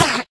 Sound / Minecraft / mob / endermen / hit4.ogg